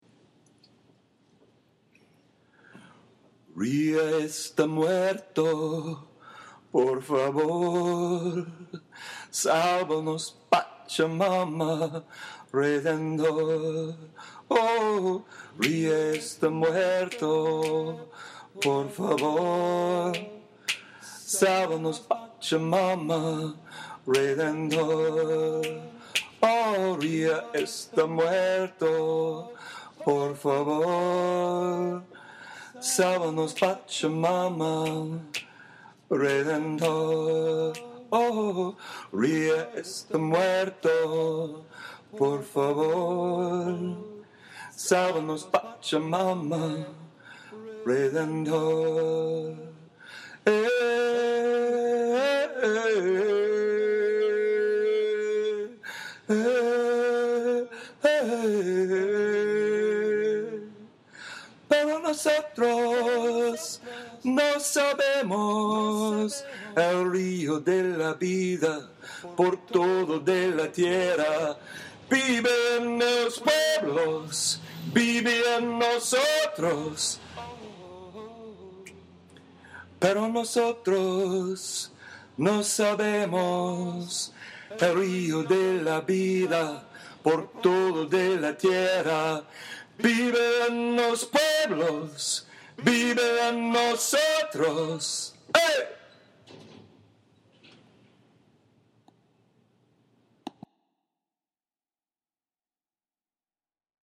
A parade of Civil Society members walked out of  the  negotiation “Rio Centro” for Rio+20 yesterday, many of them abandoning their entrance badges, shouting “The Future We Want Is Not Found Here!”